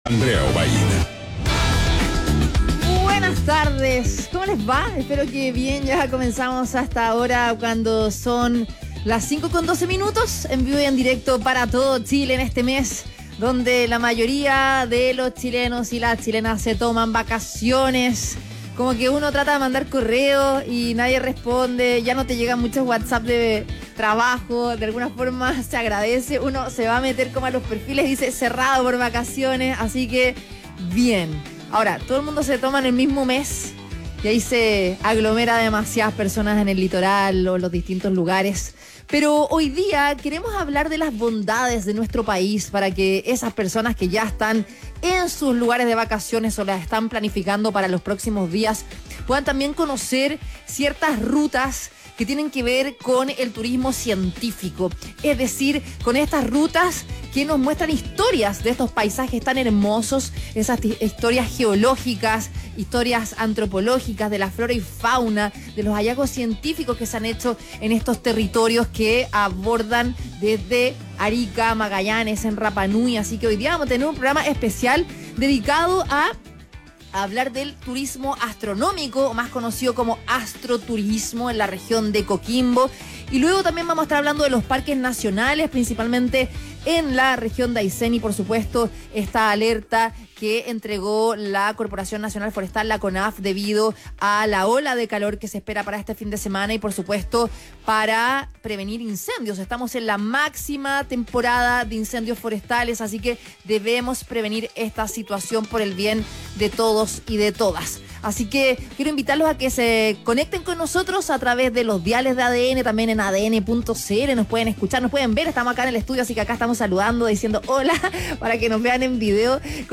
Entrevista a Marios Aros, alcalde de Vicuña y el astroturismo